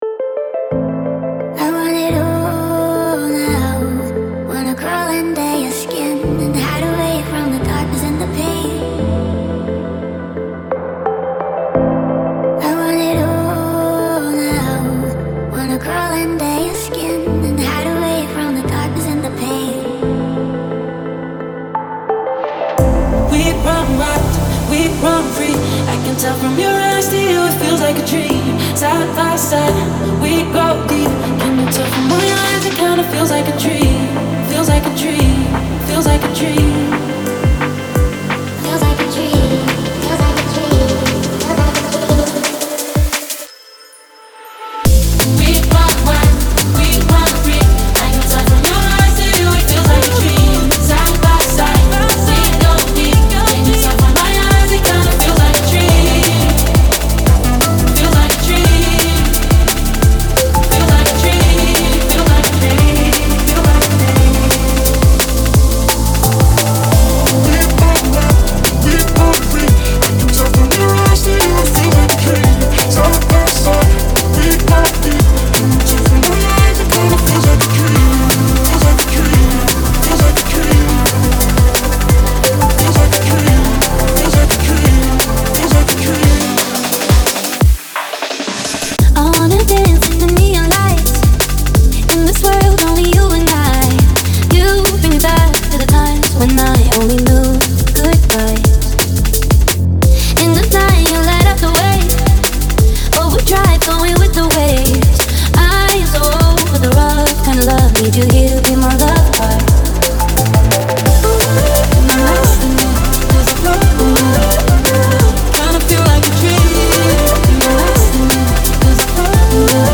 • Жанр: Dram&Bass